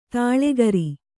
♪ tāḷe gari